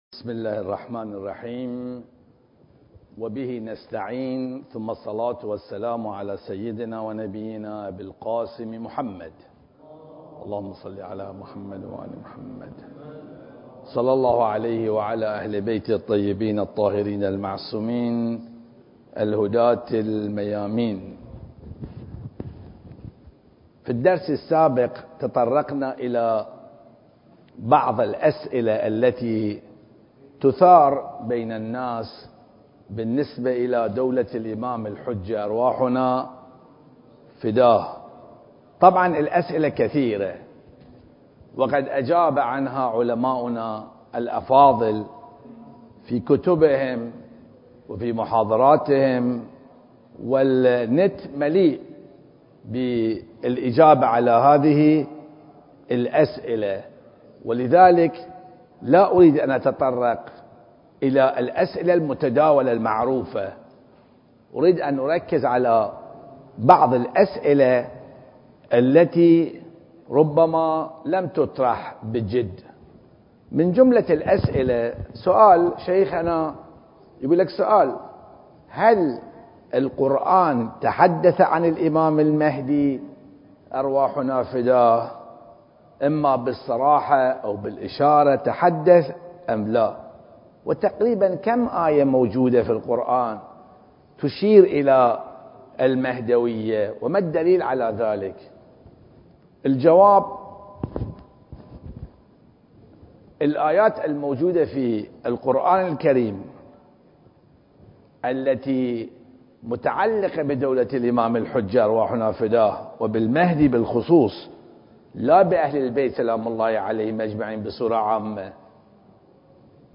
أسئلة مهدوية (2) المكان: مسجد الغدير - البحرين التاريخ: 1442 للهجرة